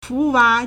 福娃 fúwá
fu2wa2.mp3